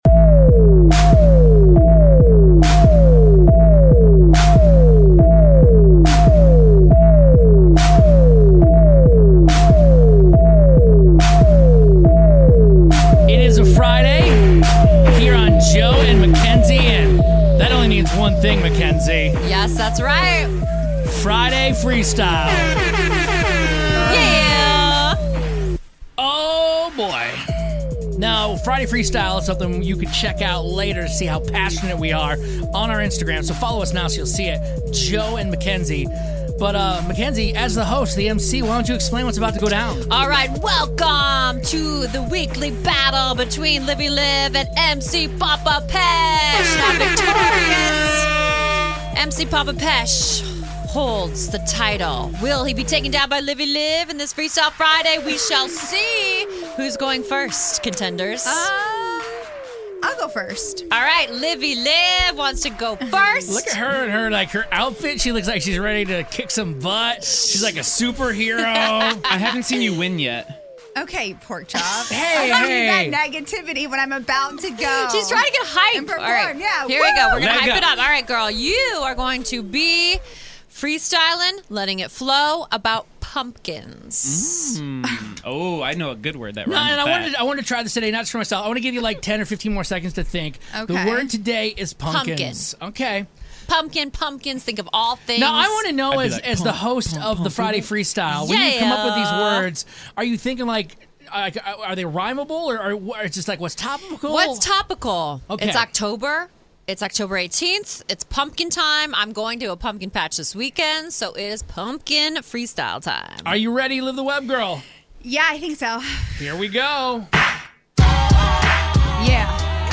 Friday Freestyle Rap Battle - 10/18/19